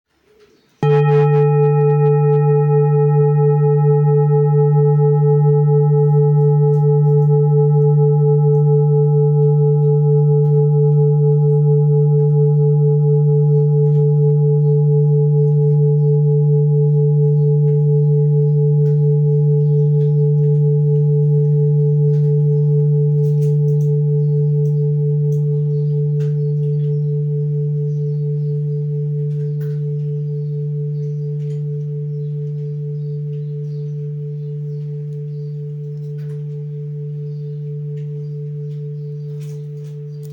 Full Moon Bowl, Buddhist Hand Beaten, Moon Carved, Antique Finishing, Select Accessories
Material Seven Bronze Metal
This is a Himalayas handmade full moon singing bowl. The full moon bowl is used in meditation for healing and relaxation sound therapy.